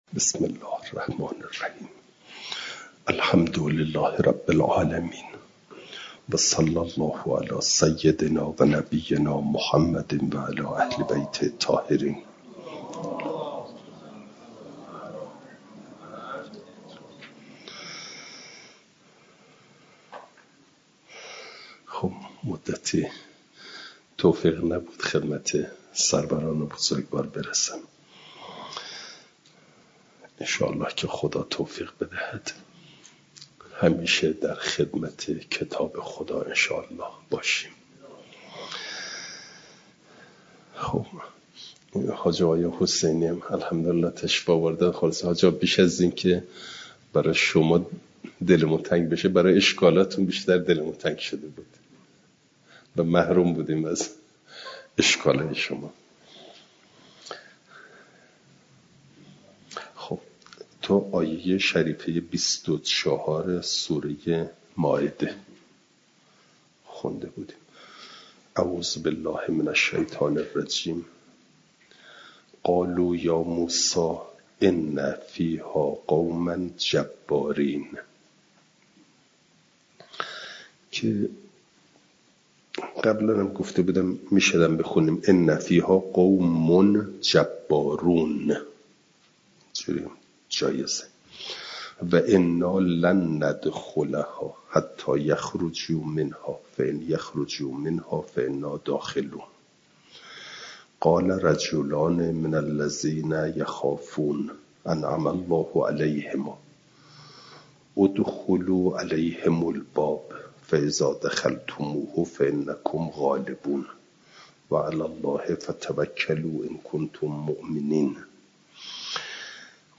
جلسه چهارصد و بیست و هفت درس تفسیر مجمع البیان